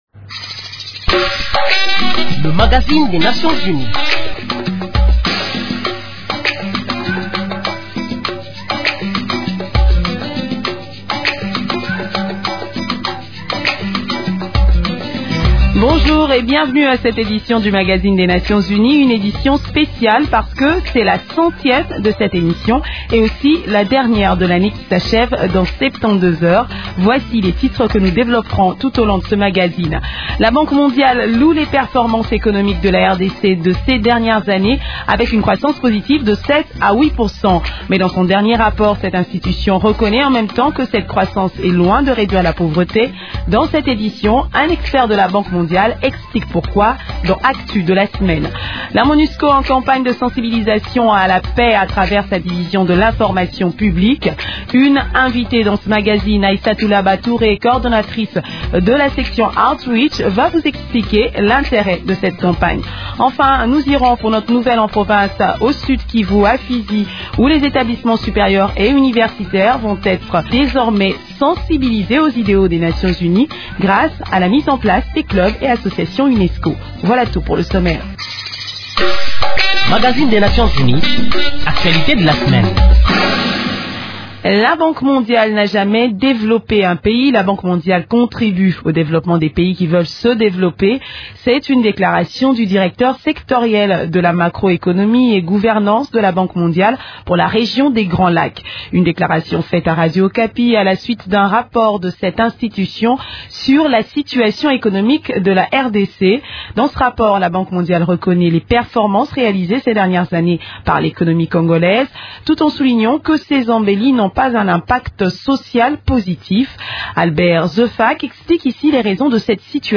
Au cours de cette émission, vous suivrez également un reportage sur la visite des casques bleus ghanéens au home de vieillards Saint Pierre dans la commune de Kinshasa et à l’orphelinat de l’Œuvre au centre de reclassement et de protection des enfants de la rue dans la commune de Kasa-Vubu.